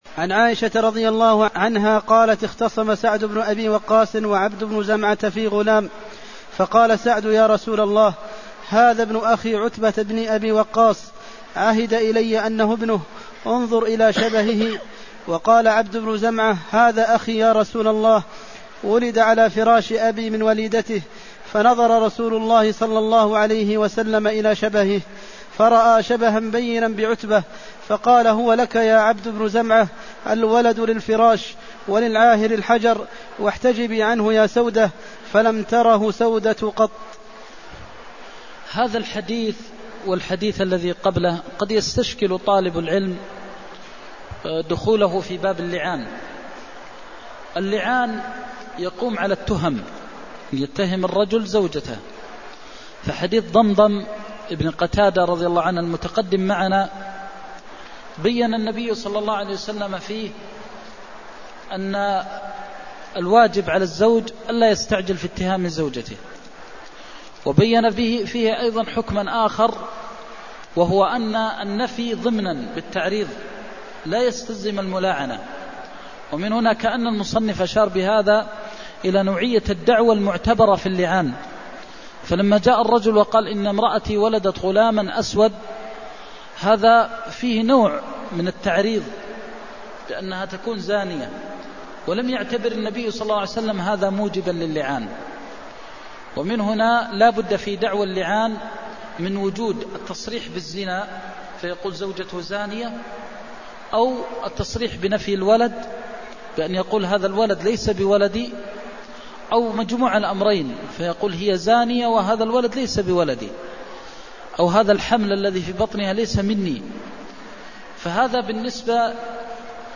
المكان: المسجد النبوي الشيخ: فضيلة الشيخ د. محمد بن محمد المختار فضيلة الشيخ د. محمد بن محمد المختار الولد للفراش وللعاهر الحجر (309)